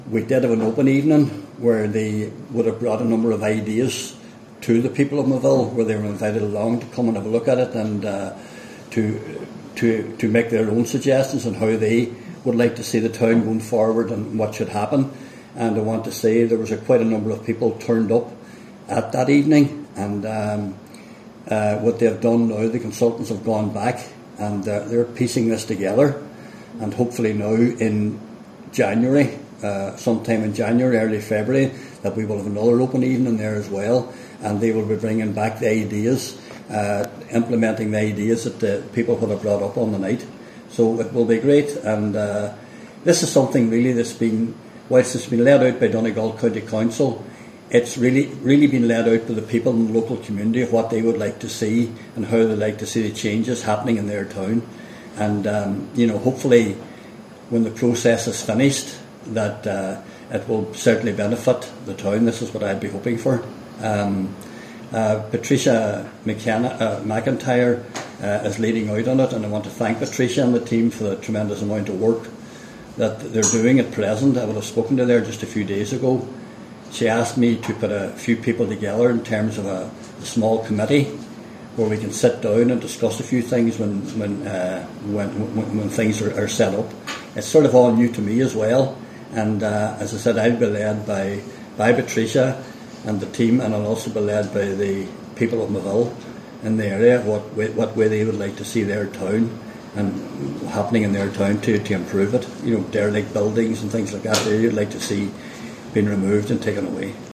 Cllr Martin Farren says it’s important that the community’s voice is at the centre of the project: